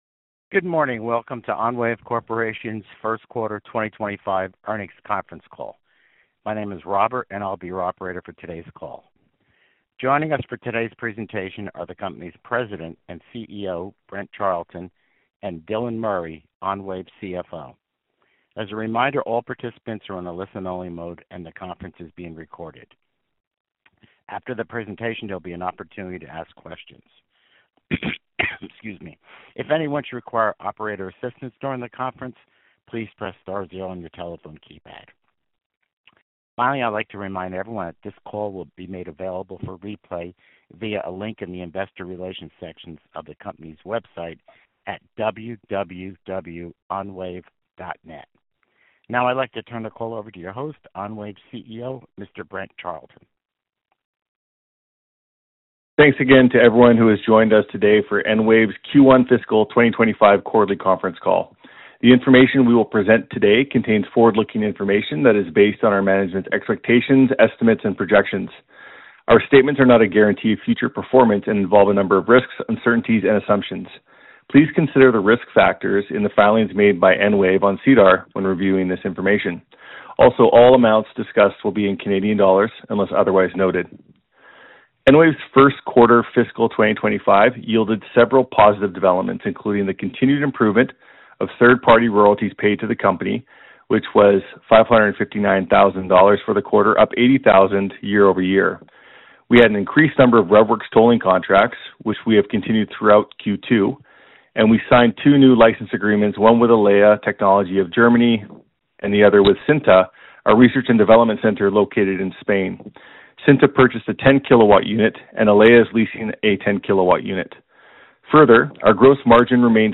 Q1 2026 Earnings Call
EnWave-Corporation_Q1-Earnings-Call.mp3